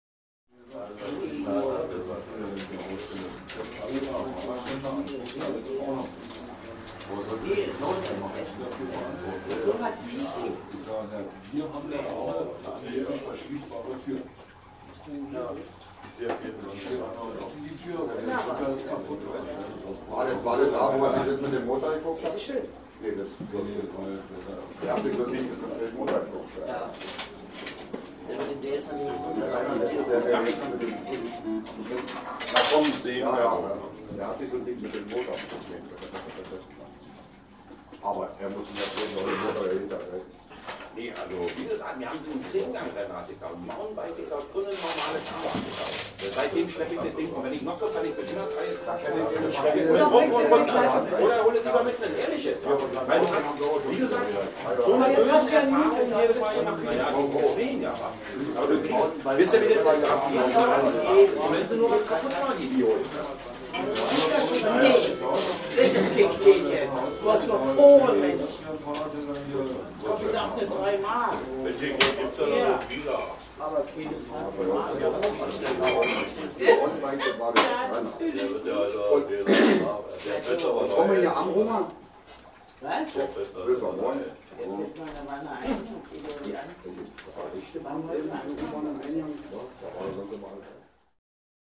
atmo_bar.mp3